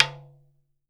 05 TALKING D.wav